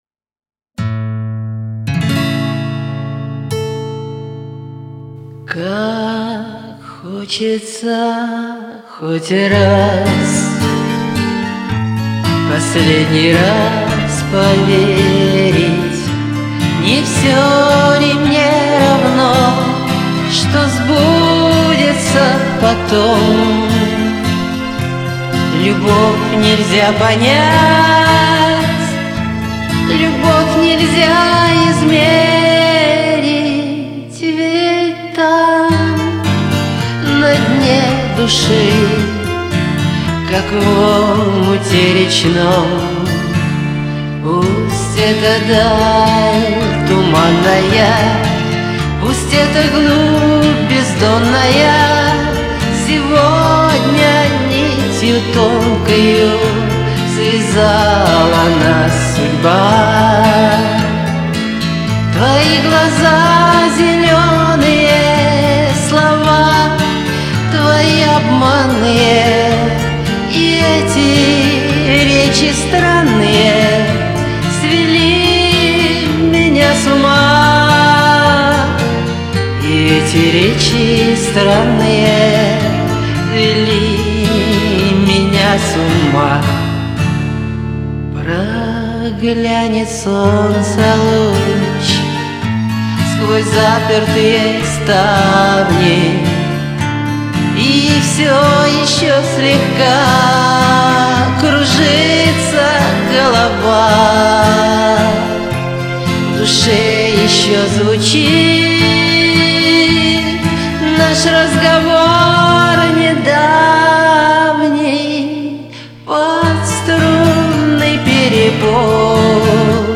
старинный романс, несколько поколений исп-лей